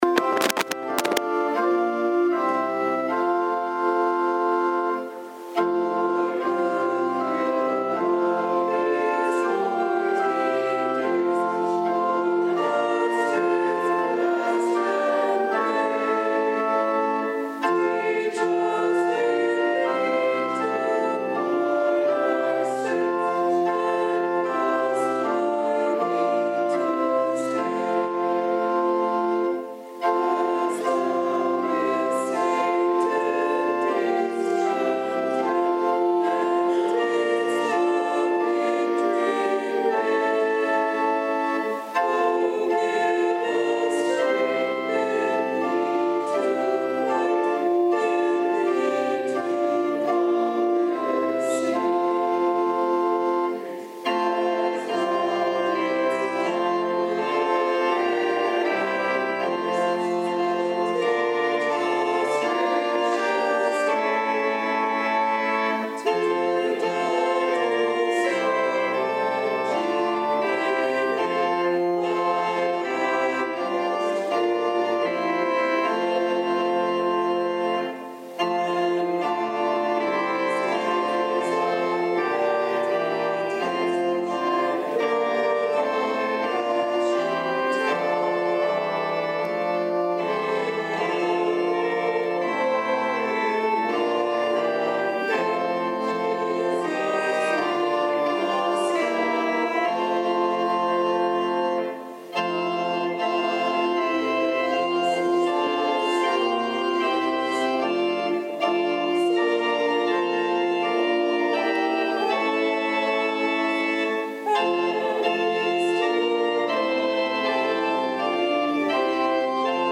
Readings and Sermon February 22 – Saint Alban's Anglican Church